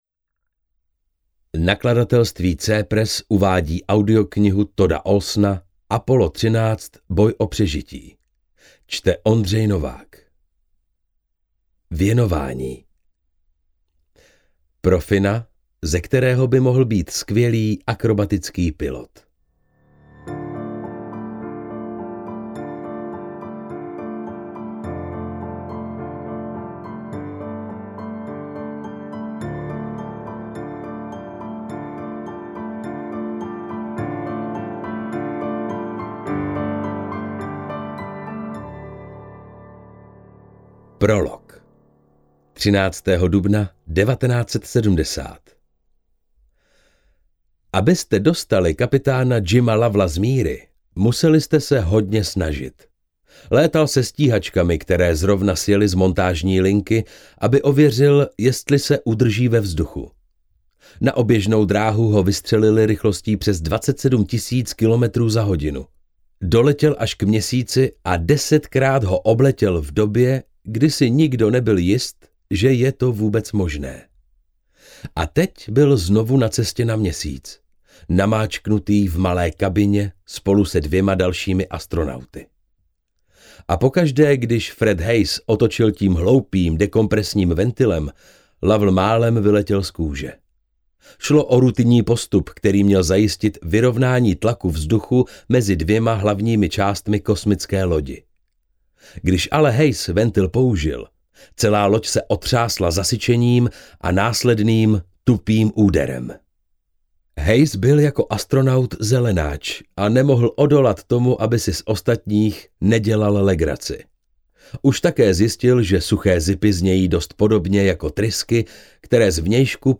AudioKniha ke stažení, 16 x mp3, délka 3 hod. 40 min., velikost 501,4 MB, česky